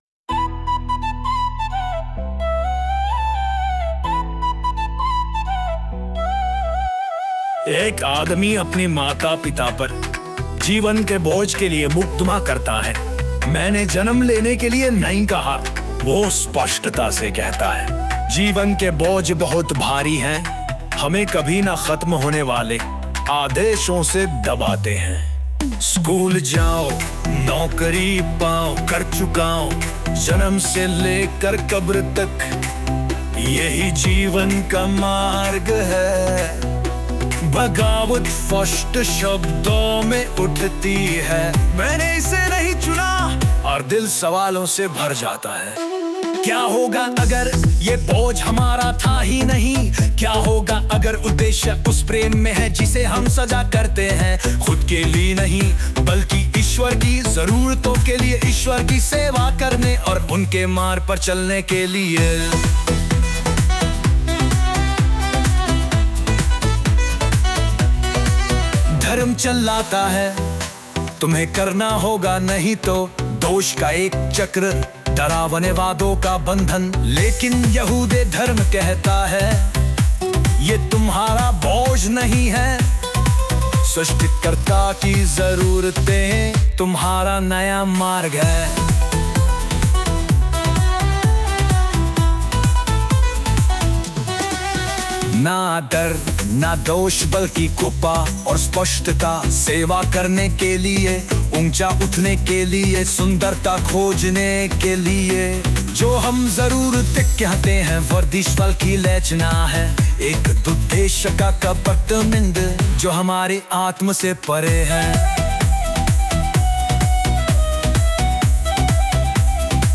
Bollywood super fast dance melody song.